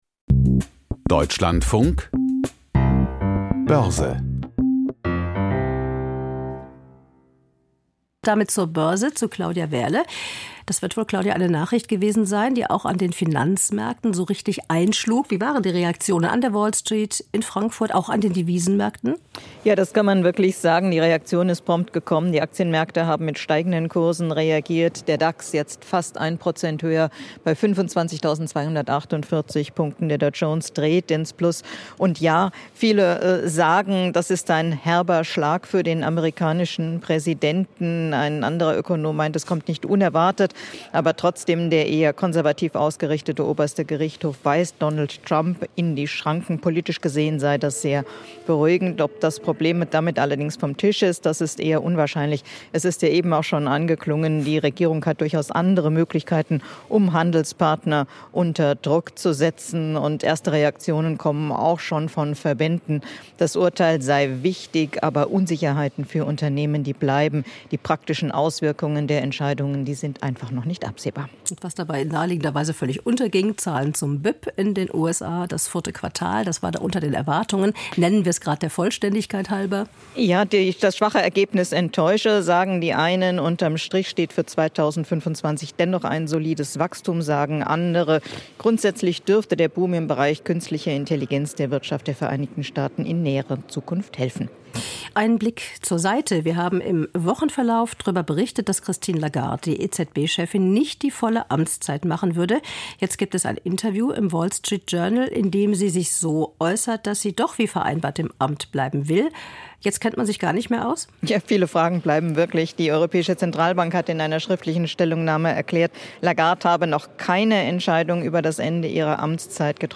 Börsenbericht aus Frankfurt a.M.